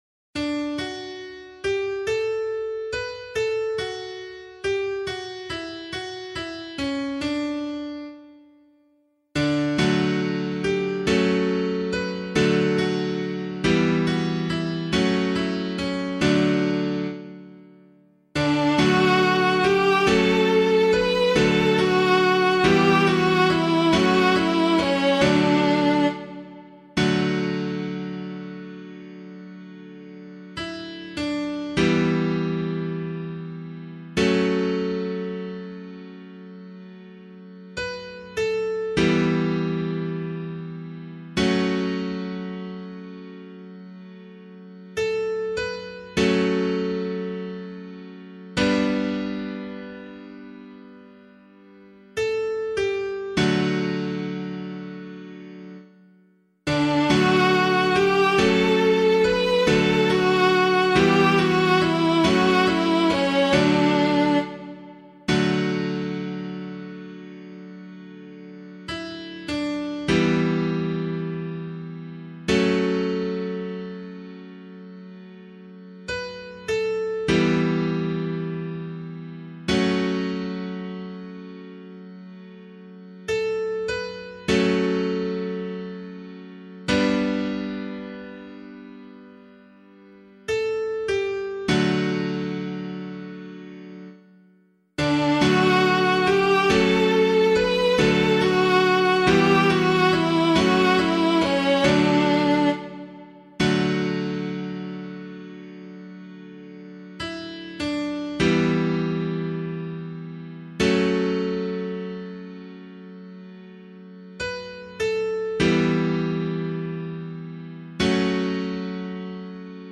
pianovocal
413 Lateran Basilica Psalm [LiturgyShare 5 - Oz] - piano.mp3